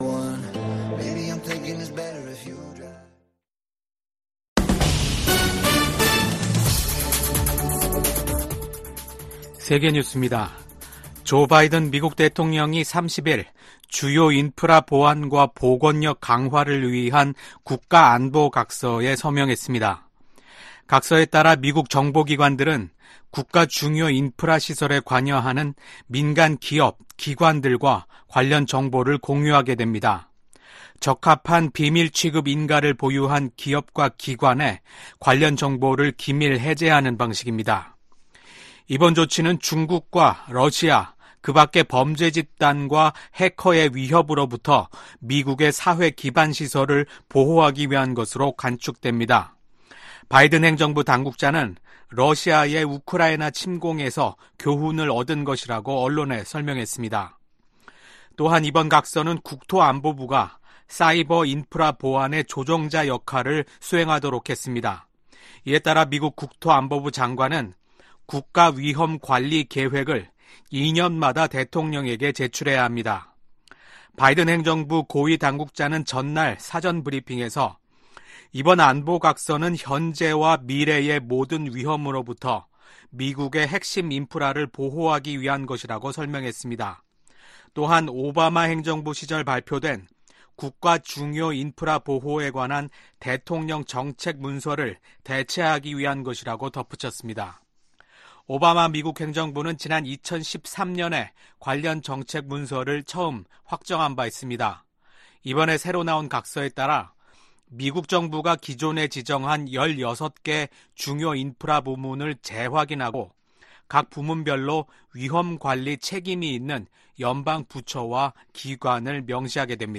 VOA 한국어 아침 뉴스 프로그램 '워싱턴 뉴스 광장' 2024년 5월 2일 방송입니다.